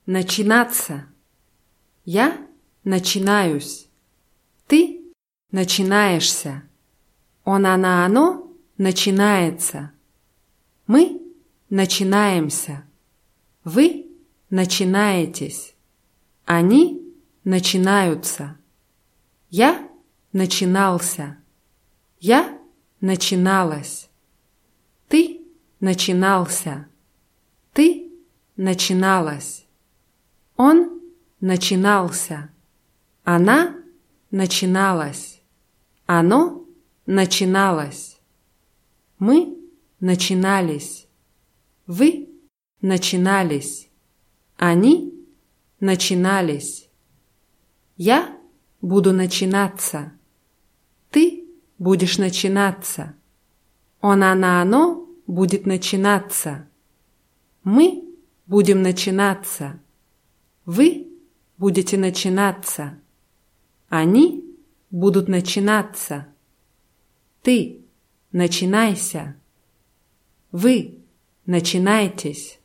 начинаться [natschinátsa]